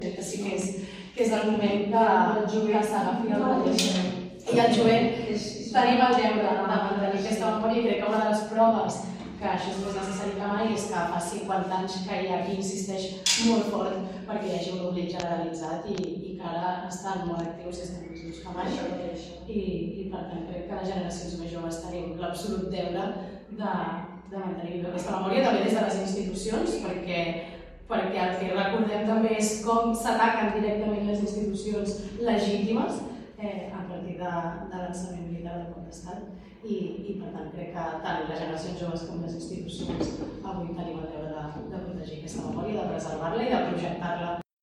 La primera tinent d’alcalde, Eulàlia Serrat, ha acceptat el relleu i ha remarcat la importància de preservar la memòria històrica, tant des de la institució com des de les generacions més joves: